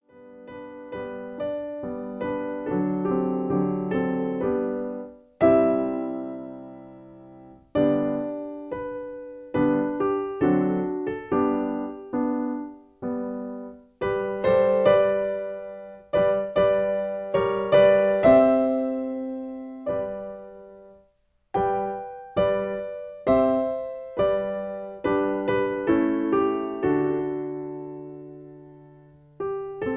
In G. Piano Accompaniment